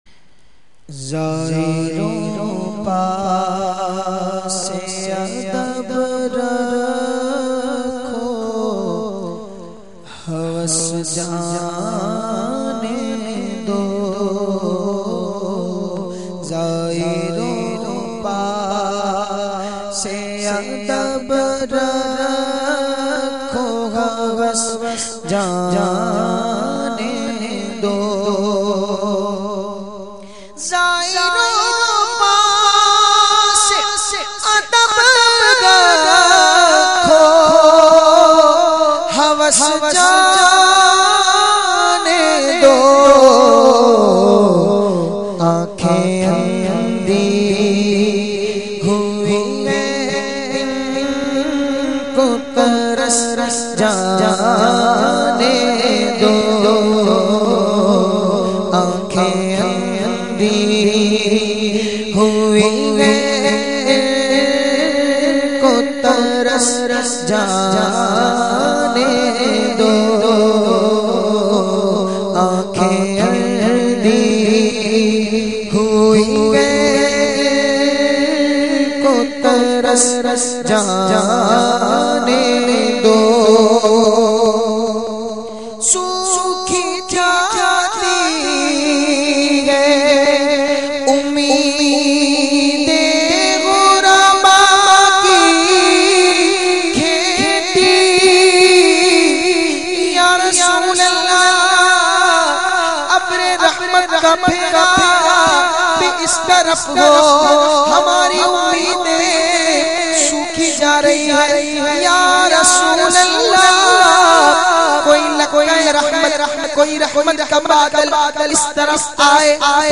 The Naat Sharif